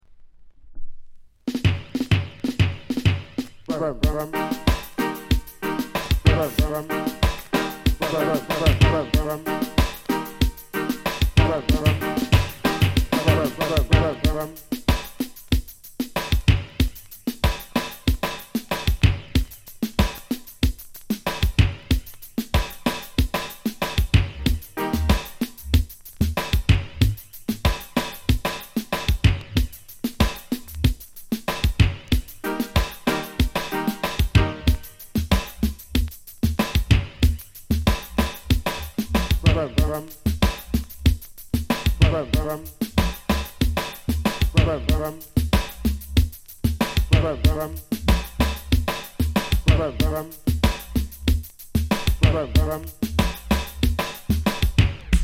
レゲエ